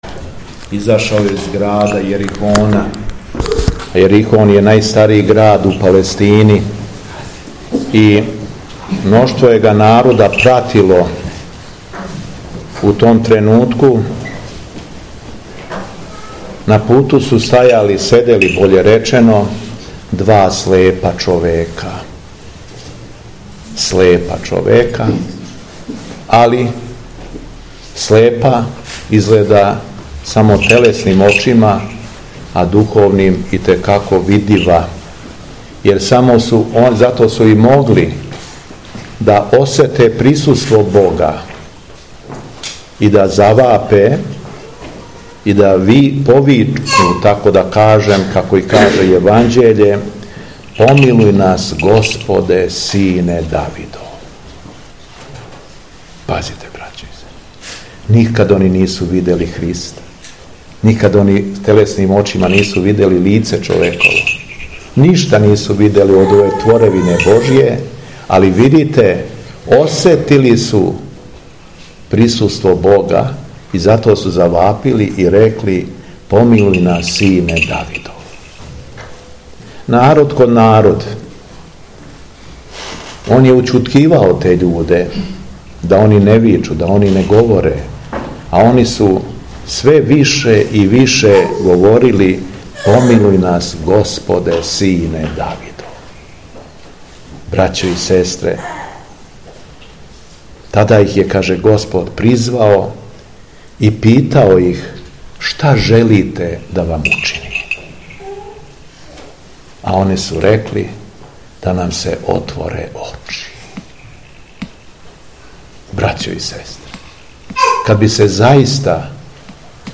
У једанаесту суботу по Педесетници, када Црква слави Оданије Преображења, епископ шумадијски господин Јован служио је Свету Архијерејску Литургију у храму Покрова Пресвете Богородице у селу Реснику, надомак Крагујевца.
Беседа Његовог Преосвештенства Епископа шумадијског г. Јована